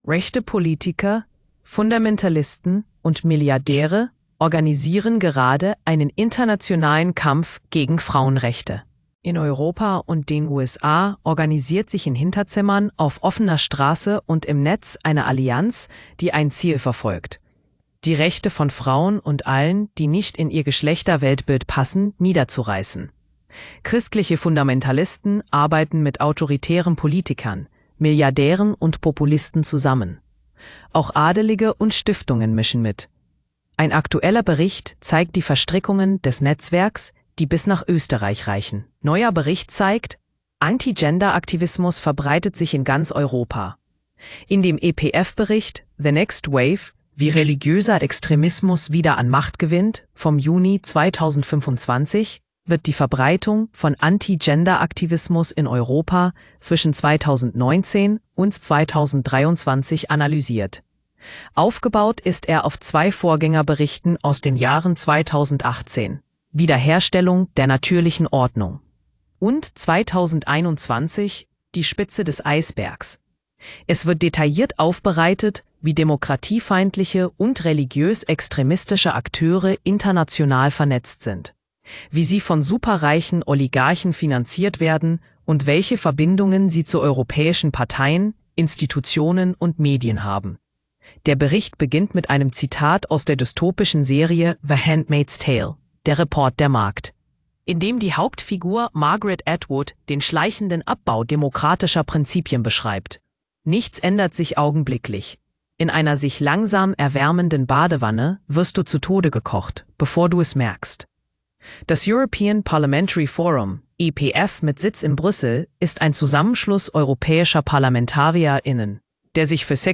Hallgassa meg az cikk hangos változatát (AI által generált).